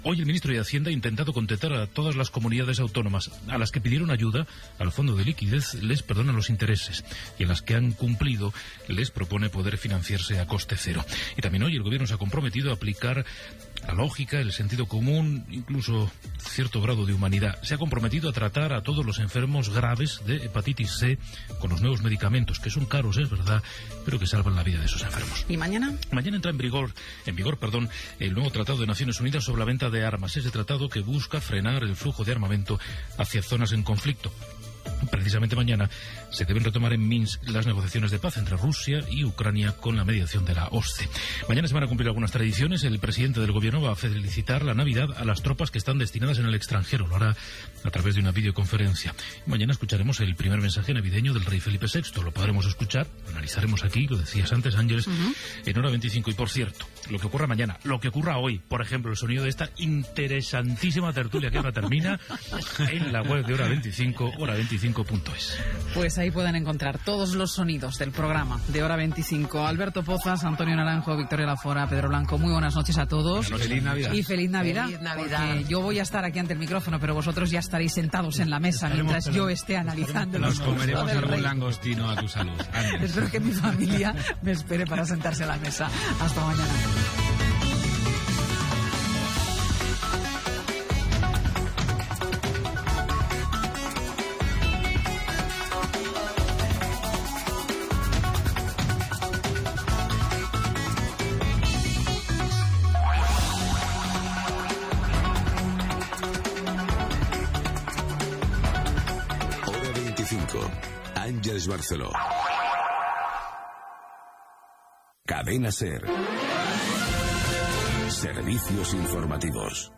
Resum informatiu i comiat del programa. Careta de sortida.
Informatiu